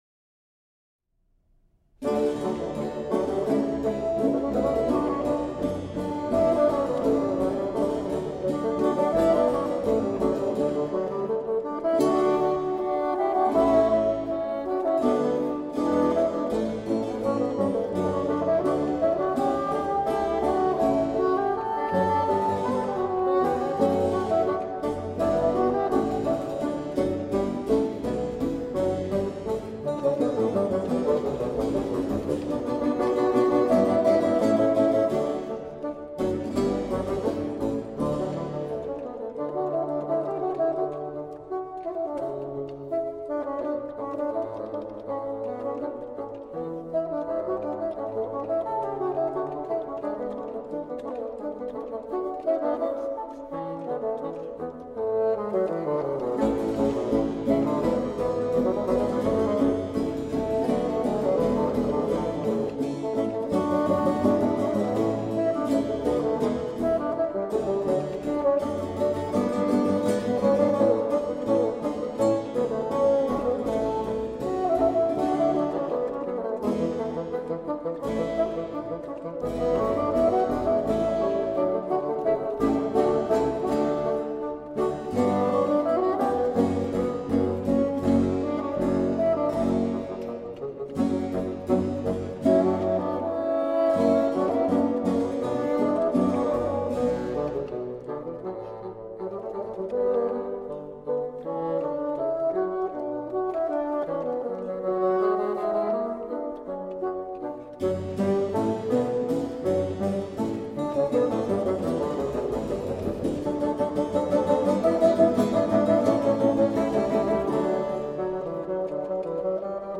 Buoyant baroque bassoon.
bright, warm tones